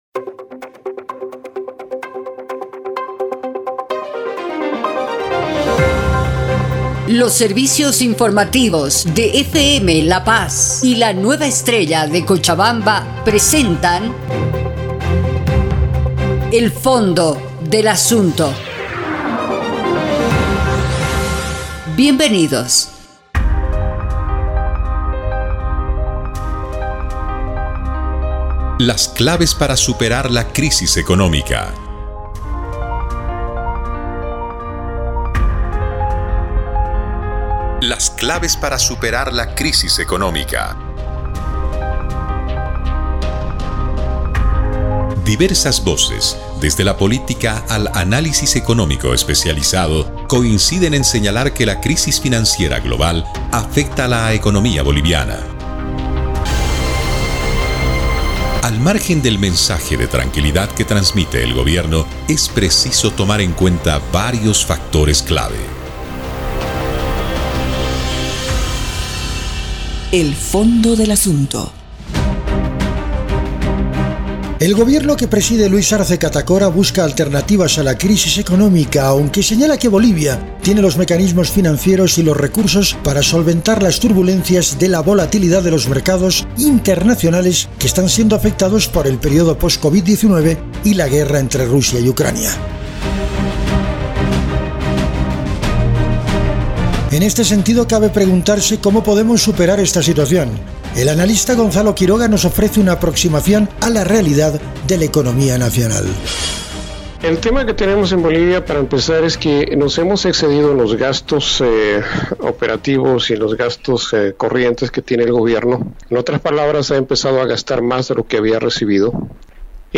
Un programa de reportajes